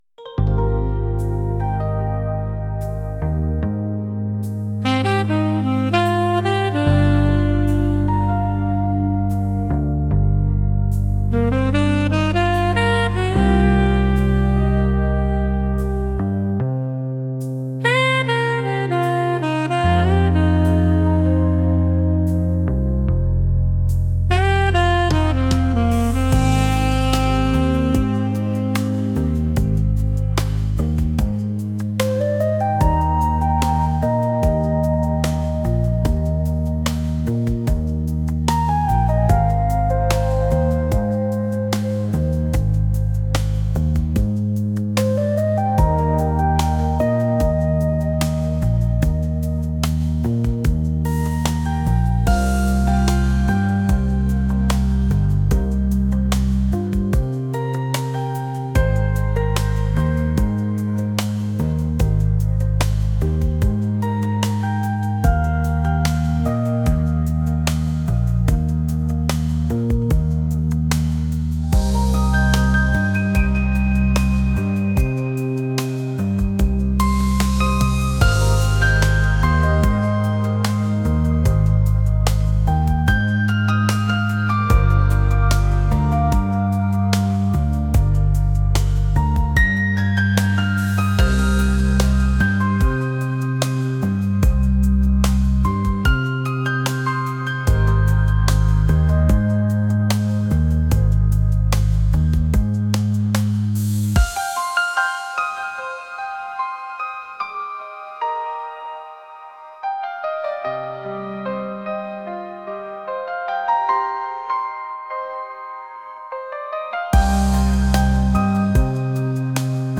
pop | jazz | retro